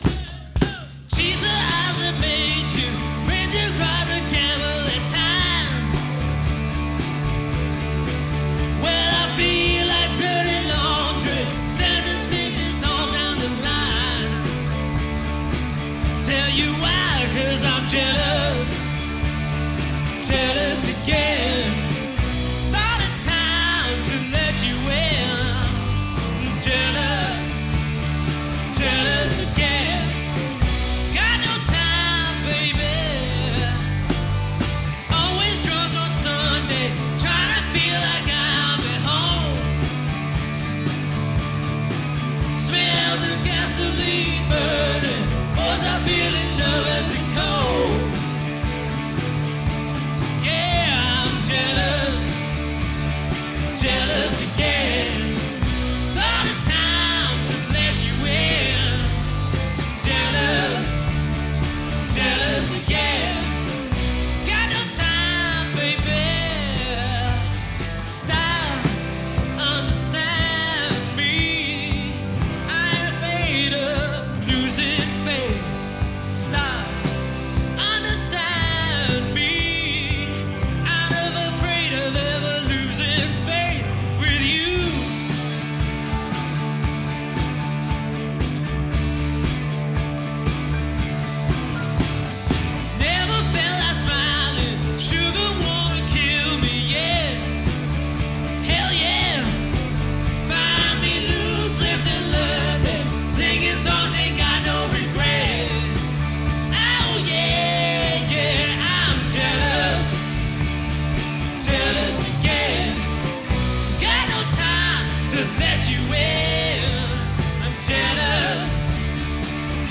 Classic rock covers from the 60s to the 2000s
lead vocals
lead guitar
rhythm guitar, vocals
drums
bass